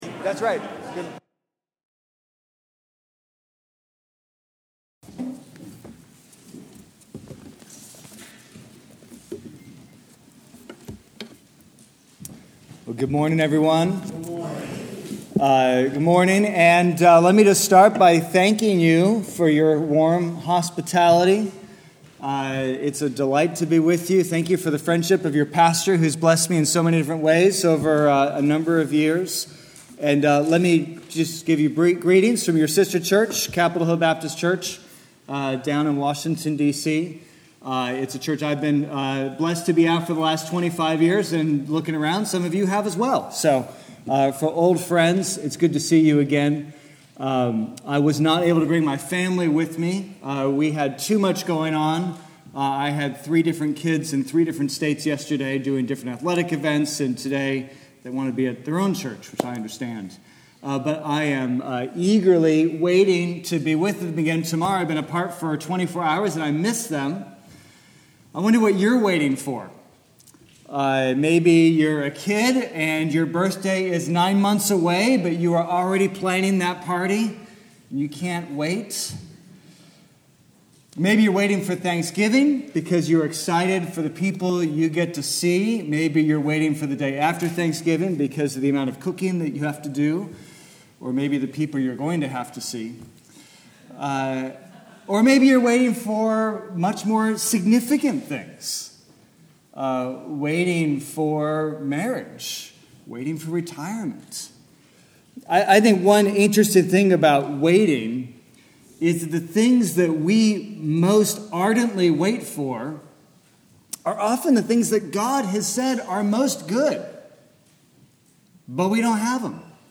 Sermon-1124-1.mp3